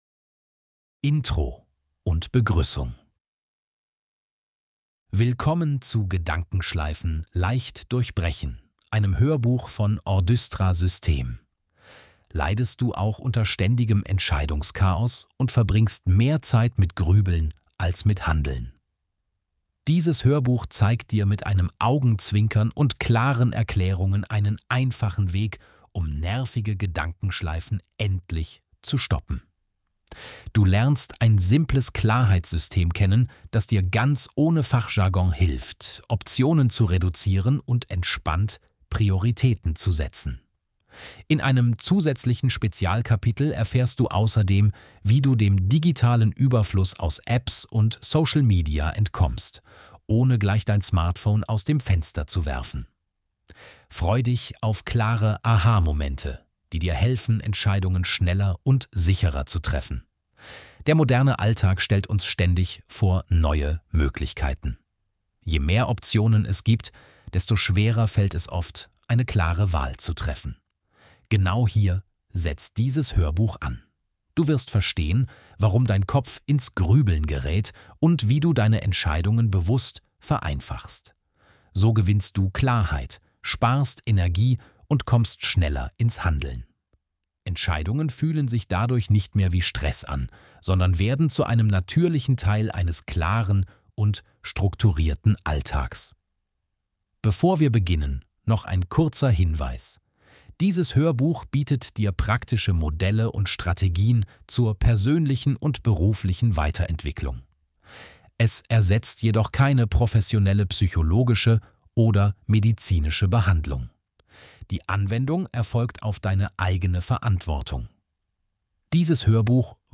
Dieses Hörbuch hilft dir, nervige Gedankenschleifen zu durchbrechen und mentale Klarheit zu finden. Lerne, wie du Optionen reduzierst, Prioritäten richtig setzt und Entscheidungen schneller triffst.
Ordystra-Band-5-gedankenschleifen-leicht-durchbrechen-kapitel-00-intro-und-begruessung.mp3